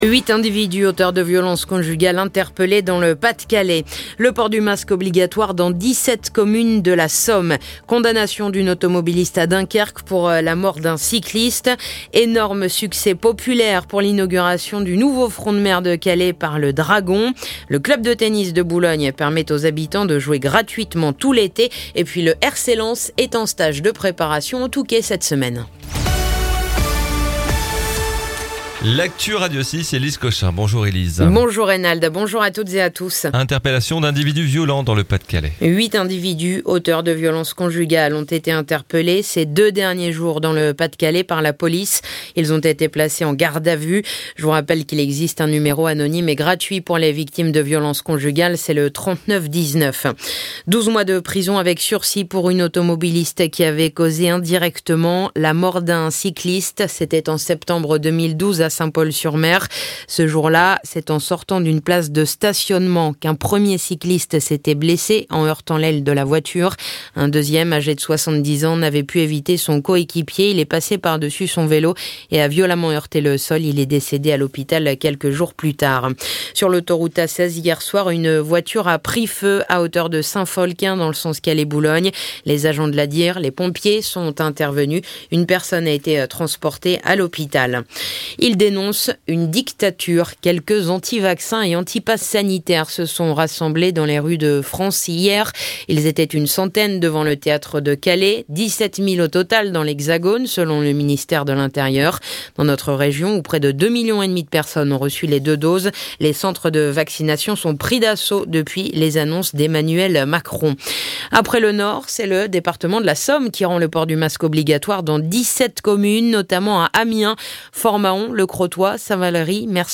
Le journal du jeudi 15 juillet